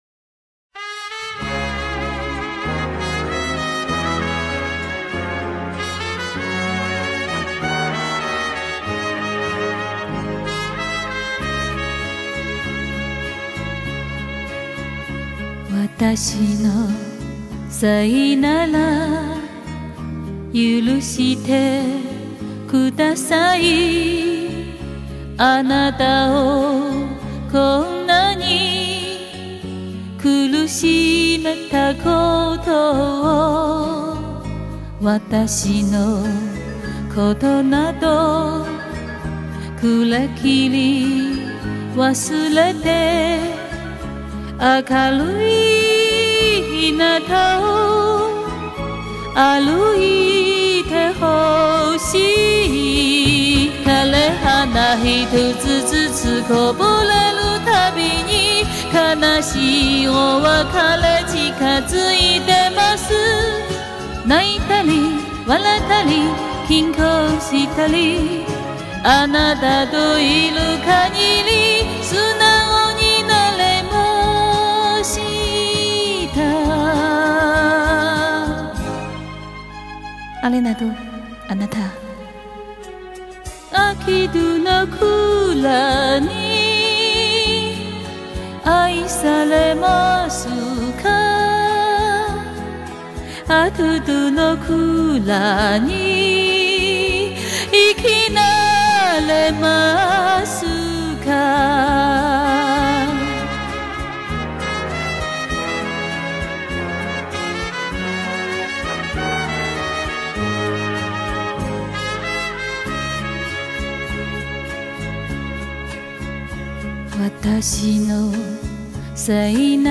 感性磁性動情的歌聲，聽得俺淚眼婆娑
不懂日語，感覺很流暢自然。